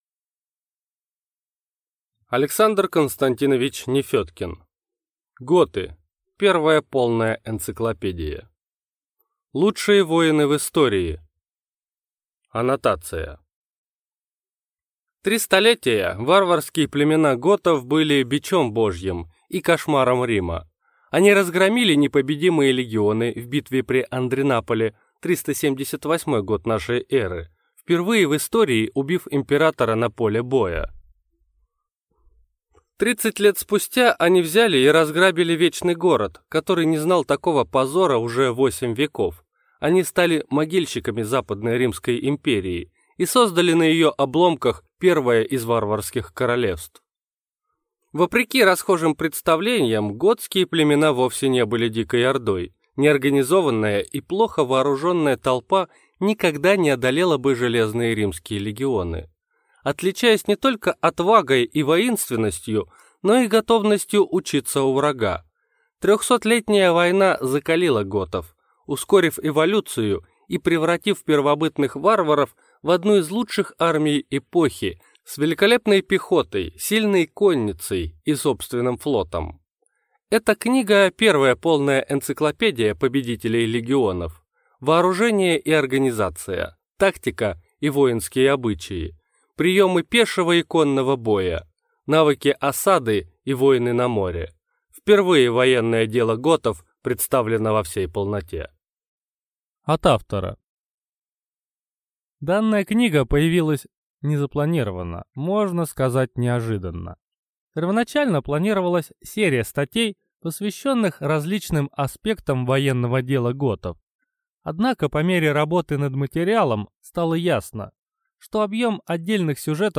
Аудиокнига Готы. Первая полная энциклопедия | Библиотека аудиокниг